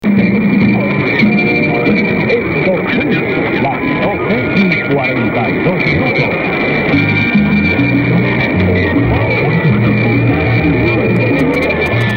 110514_0356_1390.2_unid_ss_voice_over_yl_20s.mp3